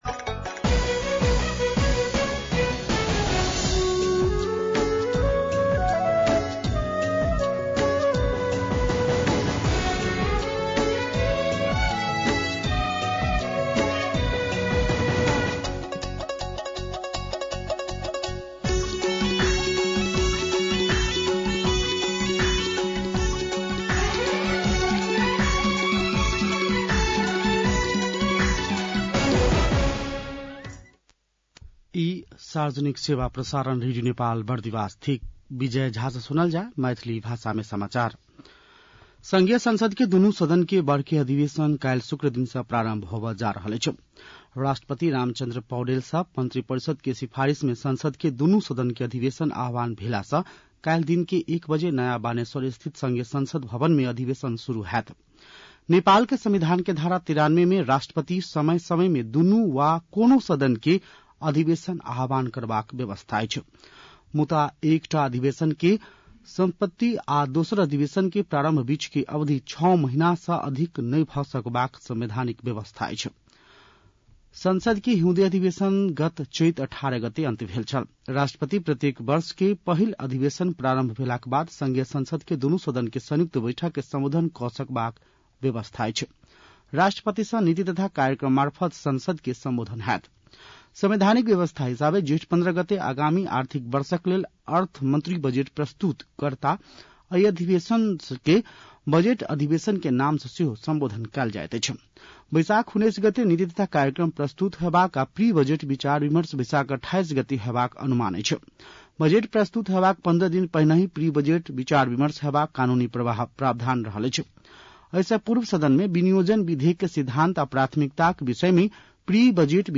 मैथिली भाषामा समाचार : ११ वैशाख , २०८२
6.-pm-maithali-news-1.mp3